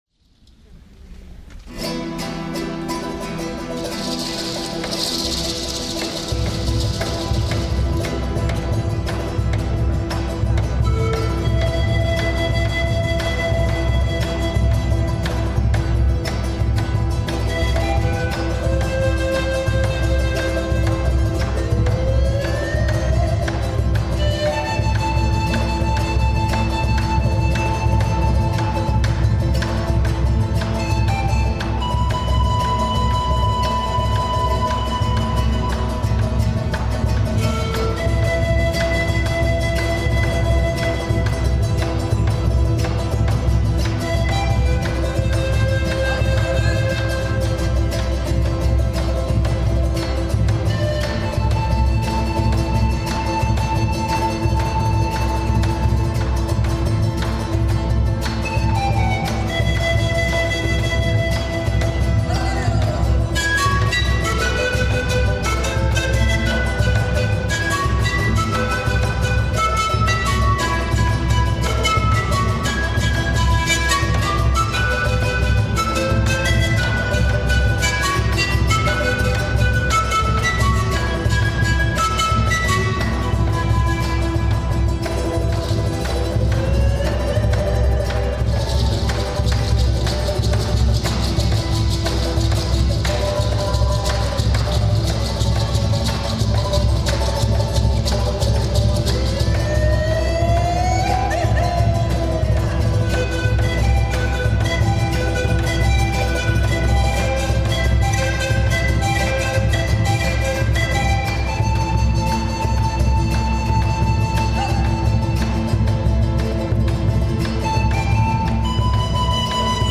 S. Gaudenzio church choir Gambolo' (PV) Italy
Dal Concerto di Natale 2004